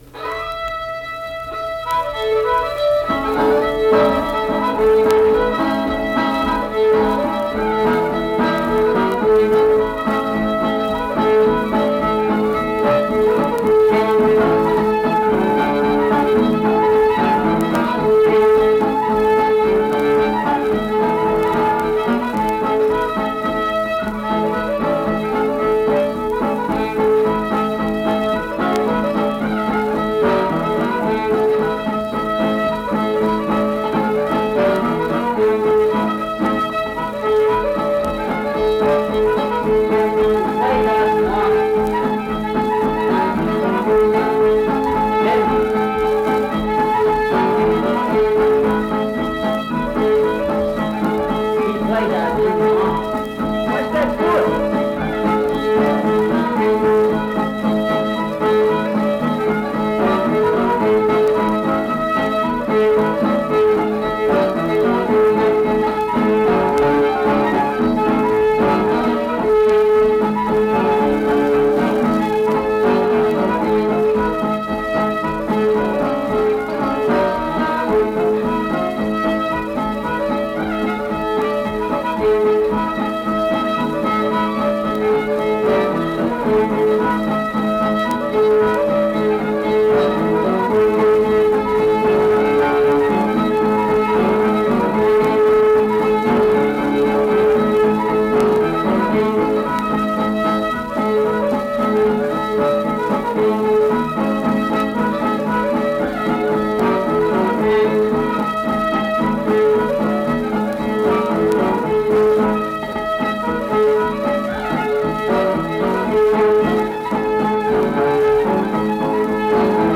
Accompanied guitar and unaccompanied fiddle music performance
Verse-refrain 6(2).
Instrumental Music
Fiddle, Guitar